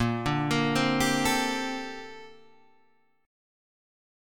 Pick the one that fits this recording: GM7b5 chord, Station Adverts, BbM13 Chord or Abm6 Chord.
BbM13 Chord